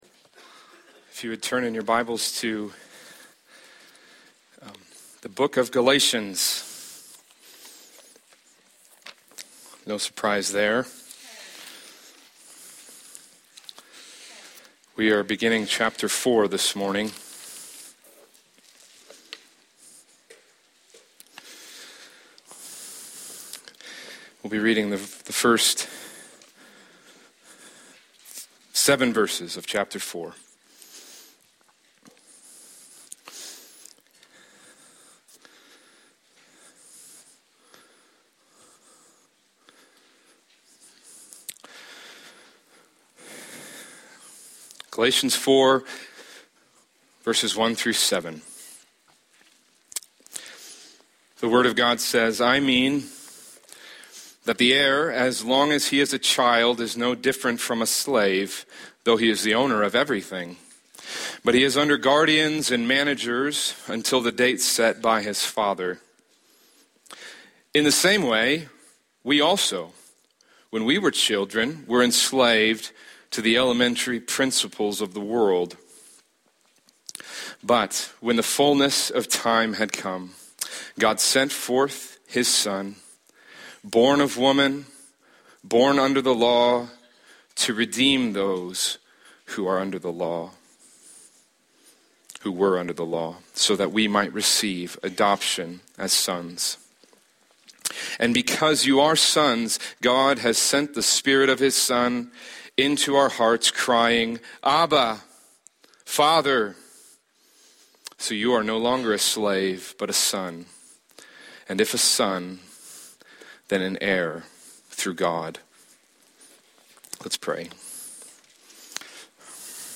Sermons | Ridgeview Bible Church